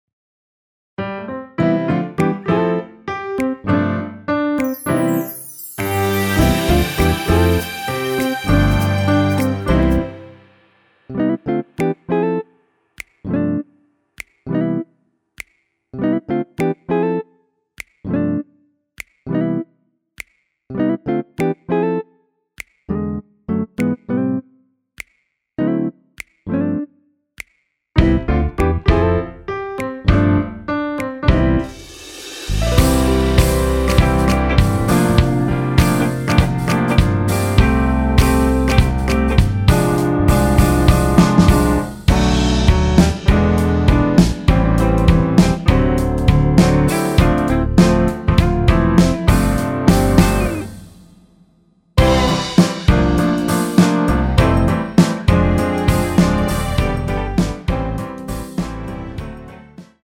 원키에서 (-3)내린 MR 입니다.
앞부분30초, 뒷부분30초씩 편집해서 올려 드리고 있습니다.